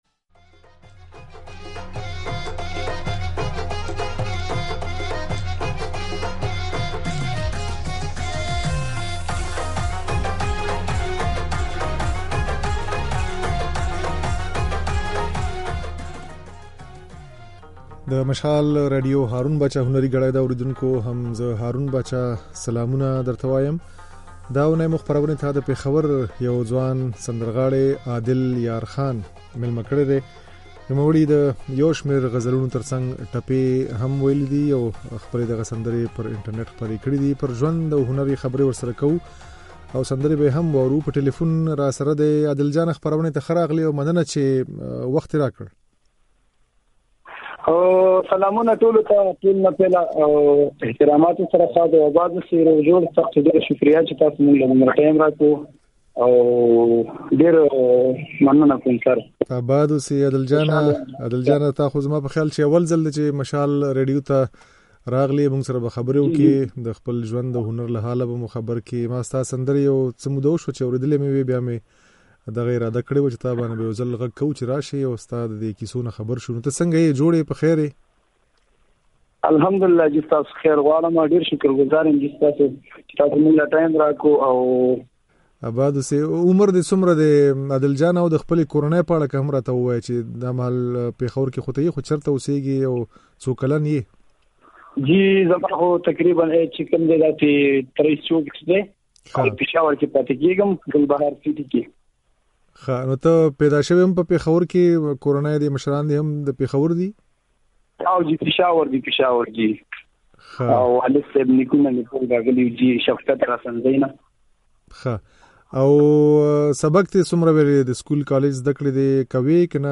د نوموړي دا خبرې او ځينې سندرې يې د غږ په ځای کې اورېدای شئ.